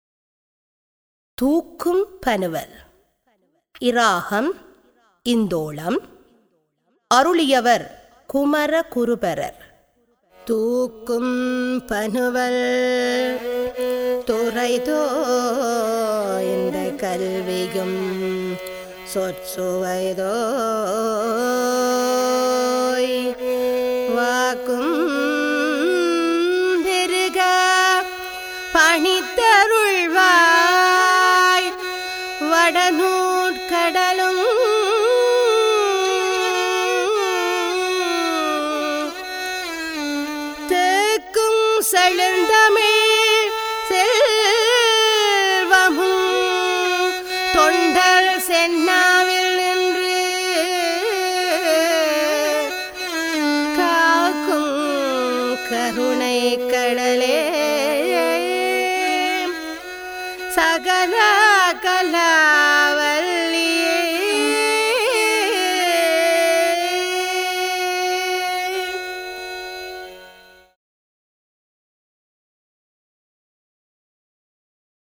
தரம் 11 - சைவநெறி - அனைத்து தேவாரங்களின் தொகுப்பு - இசைவடிவில்
தரம் 6 இல் கல்வி பயிலும் சைவநெறிப் பாடத்தை கற்கும் மணவர்களின் நன்மை கருதி அவர்கள் தேவாரங்களை இலகுவாக மனனம் செய்யும் நோக்கில் இசைவடிவக்கம் செய்யப்பட்ட தேவாரப்பாடல்கள் இங்கே பதிவிடபட்டுள்ளன.